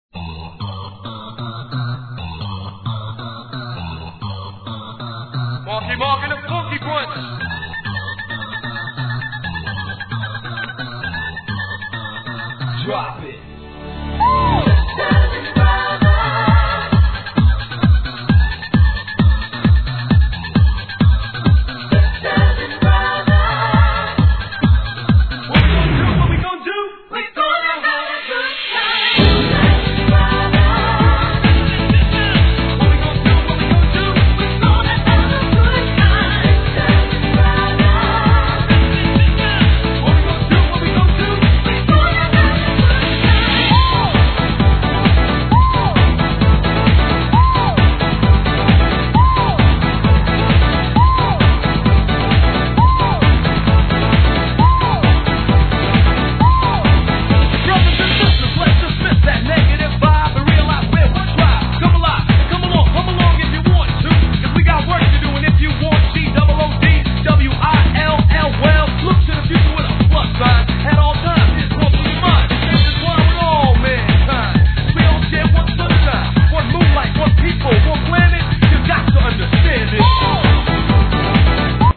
HIP HOP/R&B
1992年、ユーロビートのイケイケナンバー!!!